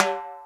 TSW TIMP 2.wav